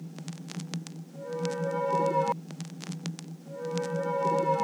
Vinyl_Tone_Layer_03.wav